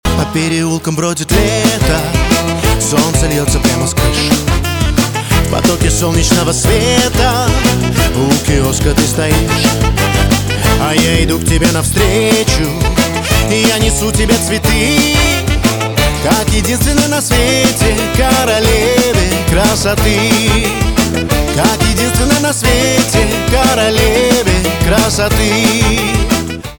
поп
романтические
барабаны , труба